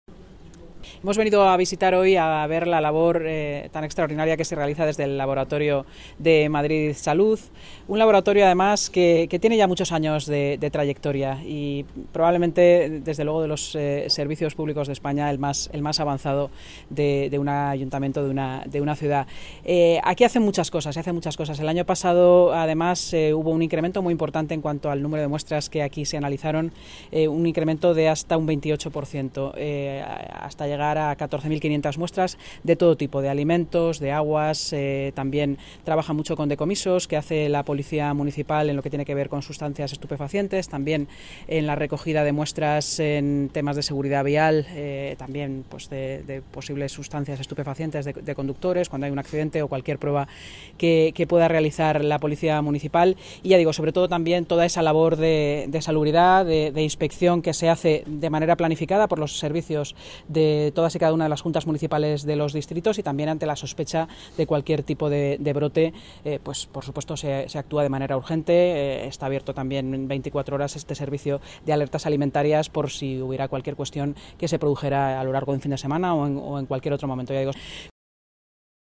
Nueva ventana:Declaraciones de la vicealcaldesa, portavoz municipal, y delegada de Seguridad y Emergencias, Inma Sanz